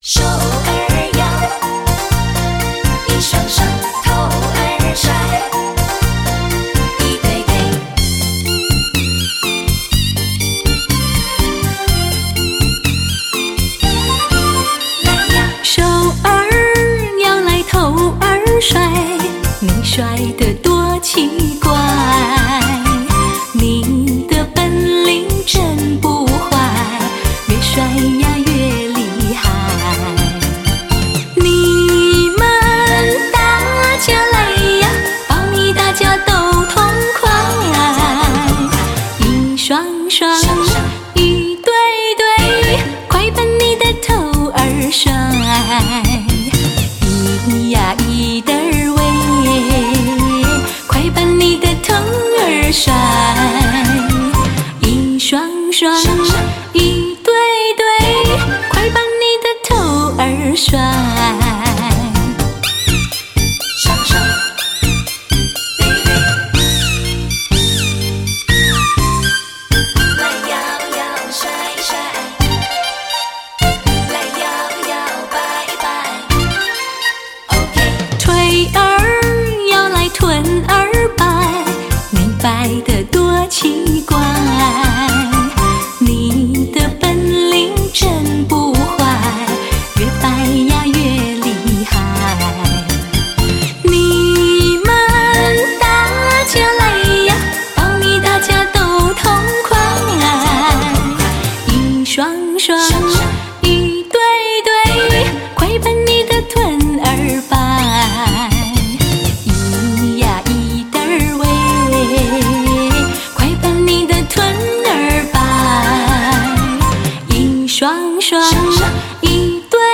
甜嗓声音情人
悱恻细致 丝丝入扣 让人一听就不忍离开...